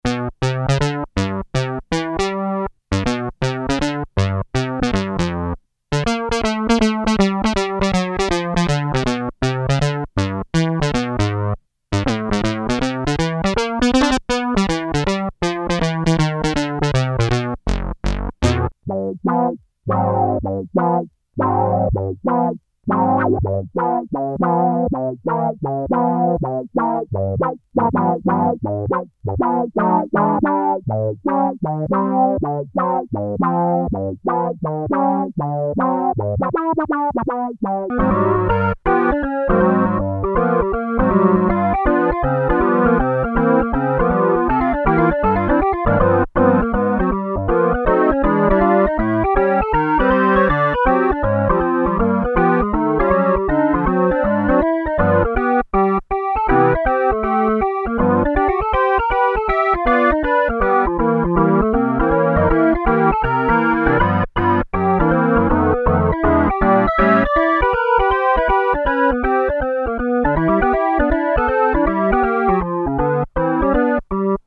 moogish